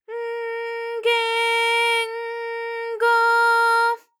ALYS-DB-001-JPN - First Japanese UTAU vocal library of ALYS.
g_N_ge_N_go.wav